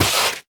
biter-roar-6.ogg